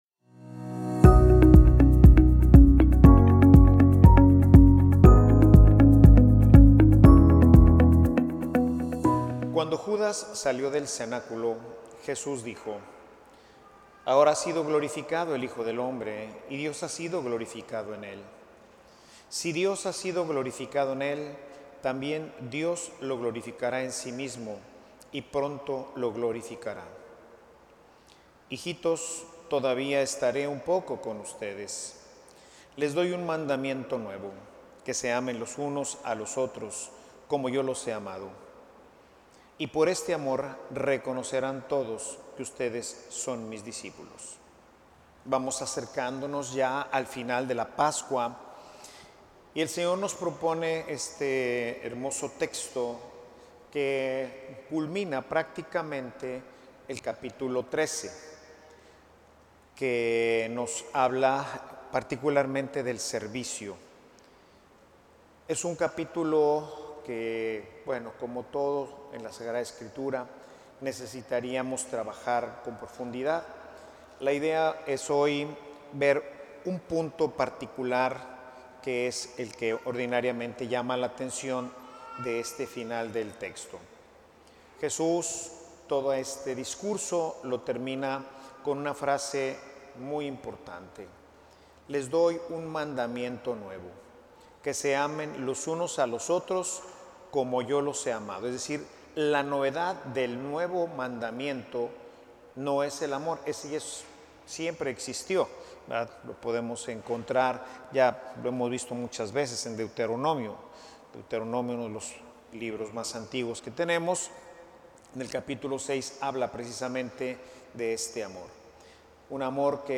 Homilia_La_escuela_de_la_cruz.mp3